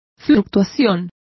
Complete with pronunciation of the translation of fluctuation.